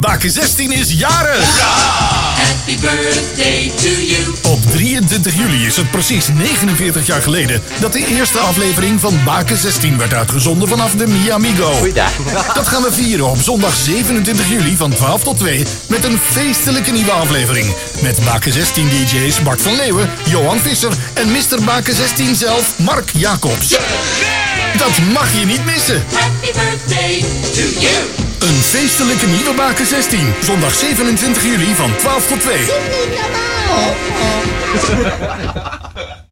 AUDIOPROMO-BAKEN-16-49-JAAR.mp3